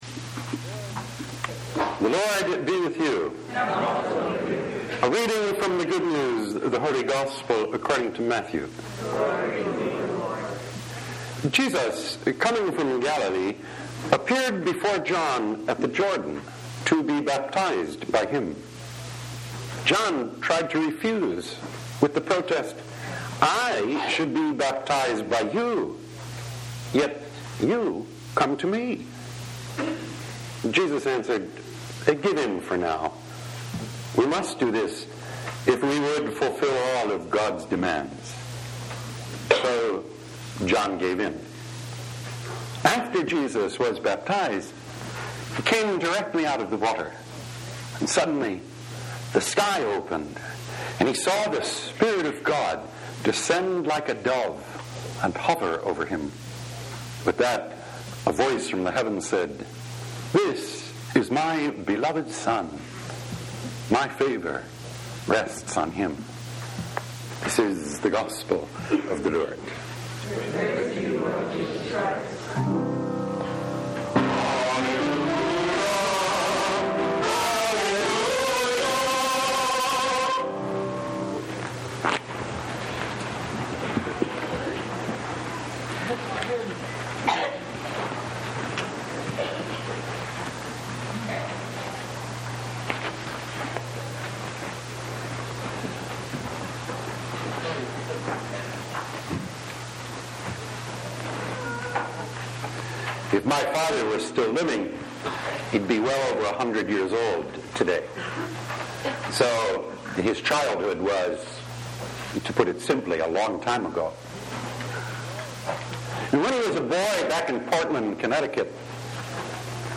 Baptism « Weekly Homilies